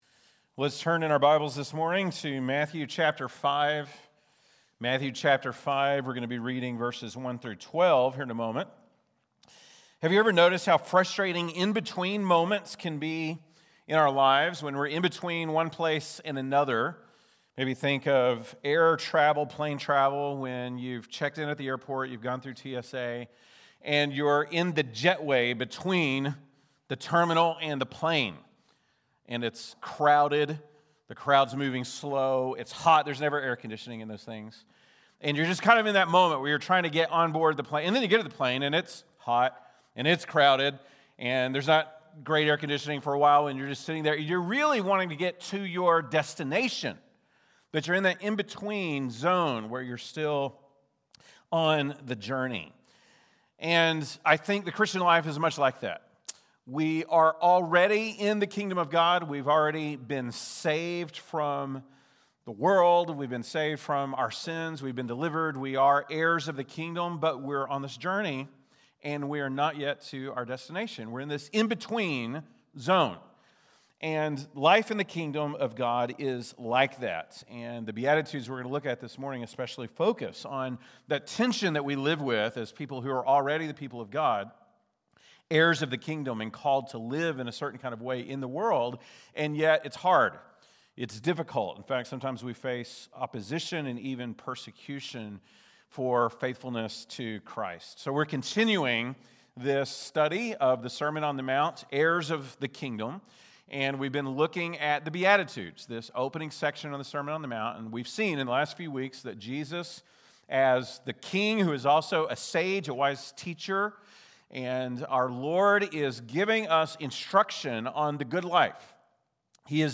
November 9, 2025 (Sunday Morning)